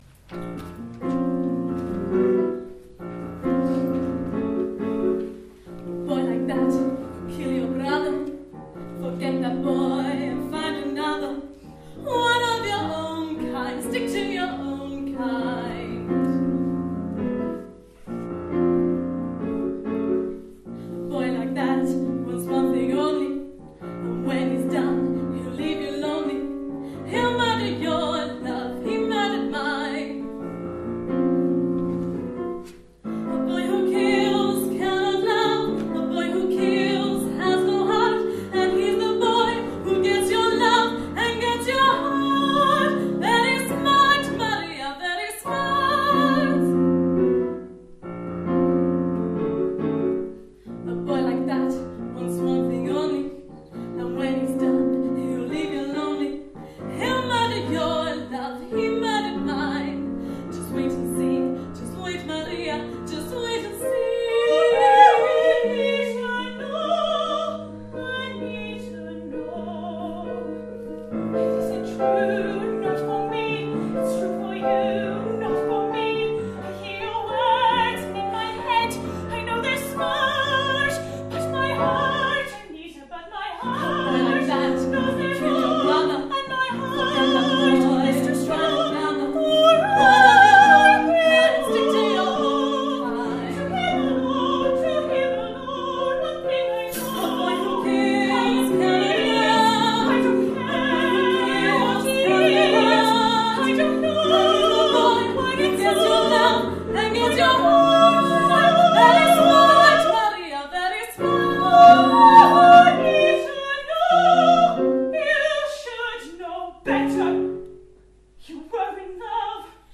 Singing Evening May 2017